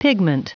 Prononciation du mot pigment en anglais (fichier audio)
Prononciation du mot : pigment